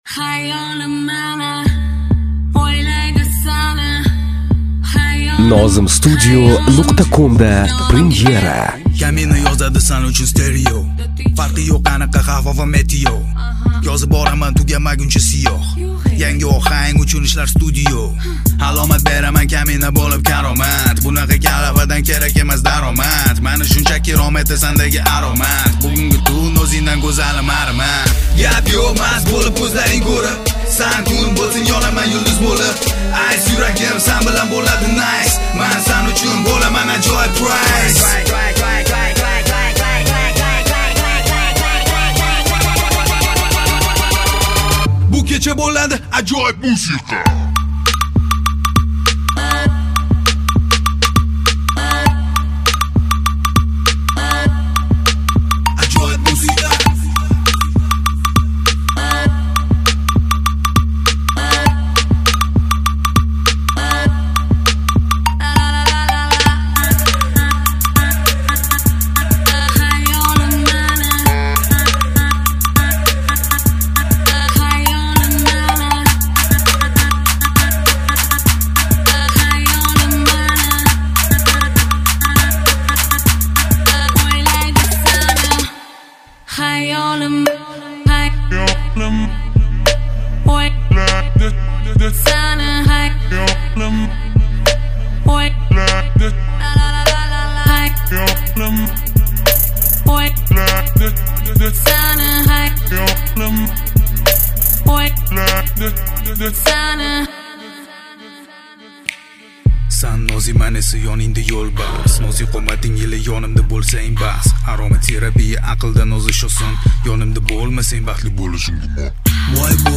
Категория: UZBEK MUSIC